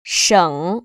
[shěng]